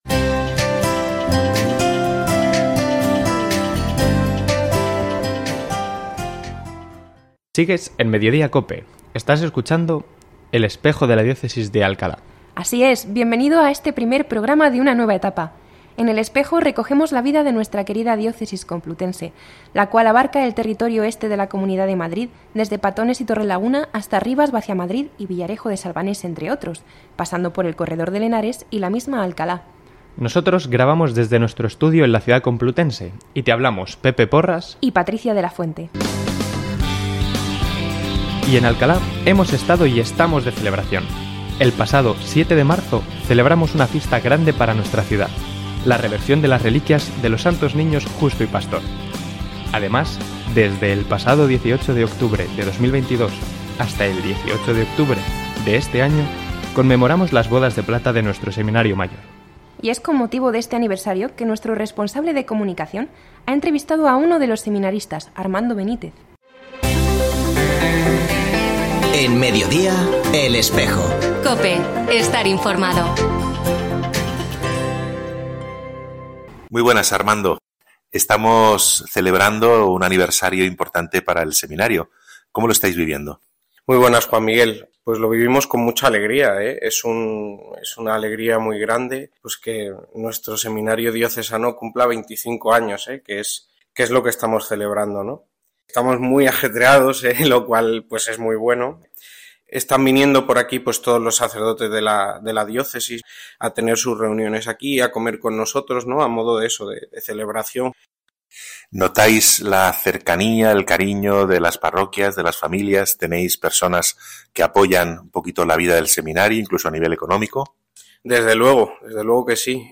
La Delegación de Medios del Obispado de Alcalá de Henares ofrecerá cada viernes, de 13.33 a 14 horas, en la frecuencia 92.0 de FM, este programa de información de la vida de nuestra Diócesis.